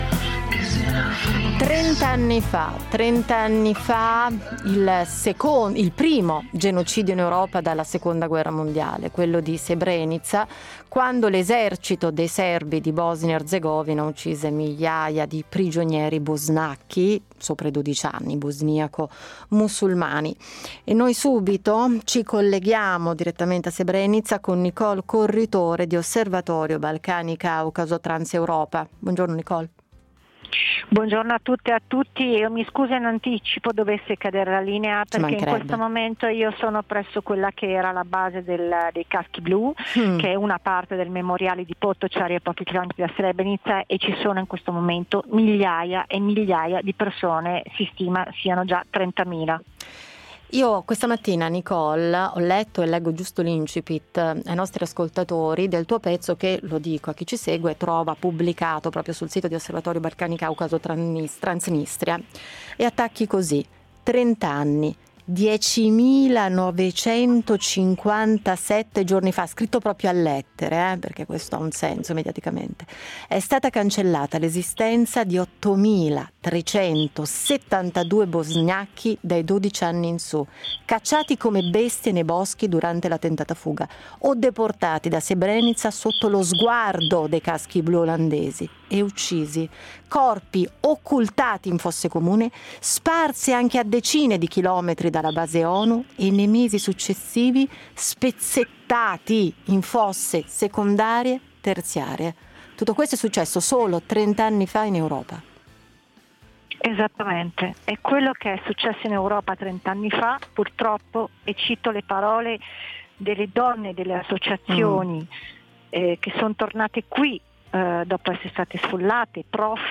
Il genocidio di Srebrenica viene commemorato oggi, 11 luglio, al Memoriale di Potočari. Strumentalizzazione del conflitto '92-'95 per fini politici, negazione delle responsabilità, narrazioni divisive tengono il paese e la regione relegati a un passato mai chiuso, mentre l'Europa non ha imparato nulla da esso. In diretta da Srebrenica